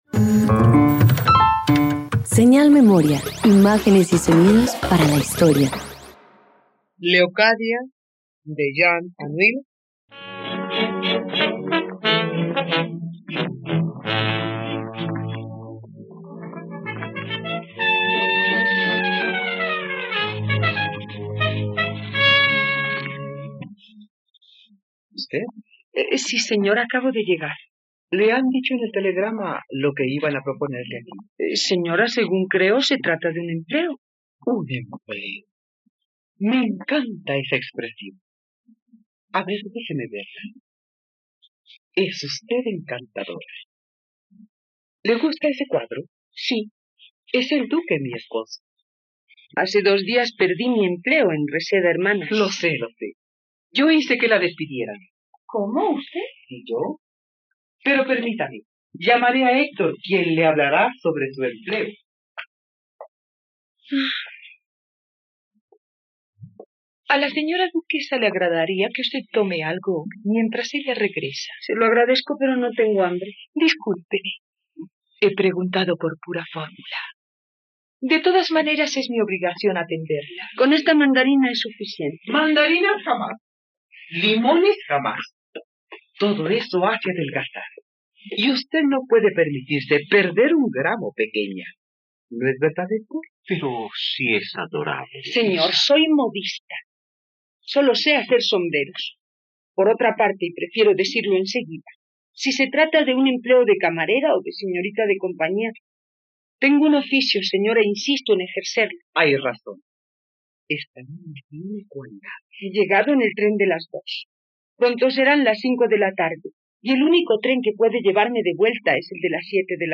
..Radionovela. Escucha ahora la adaptación radiofónica de Léocadia, obra original del escritor francés Jean Anouilh, en la plataforma de streaming RTVCPlay.
07_RADIOTEATRO_LEOCADIA.mp3